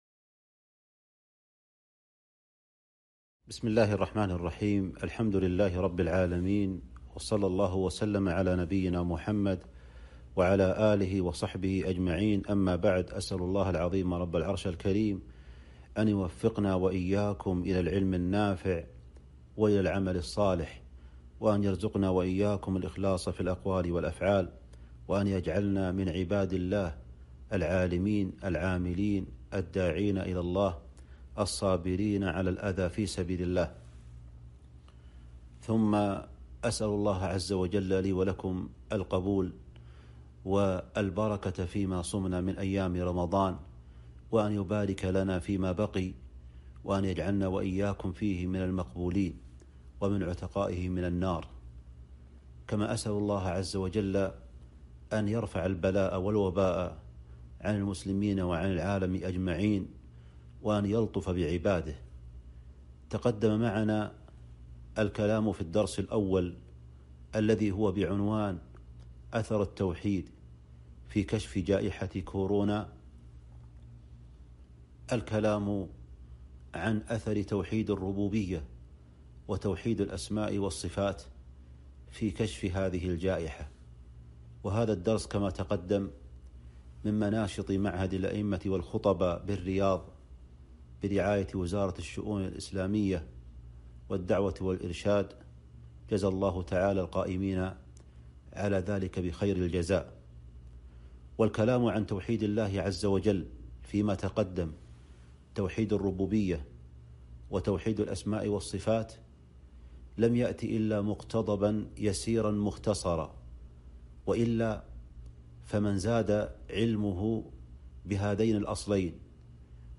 أثر التوحيد في كشف جائحة كورونا المحاضرة الثانية